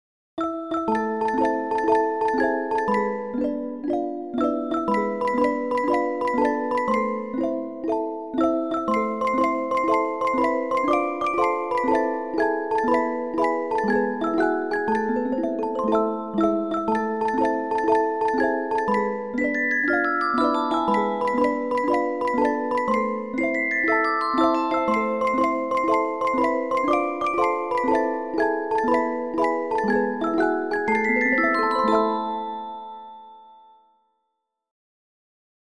folk song Spain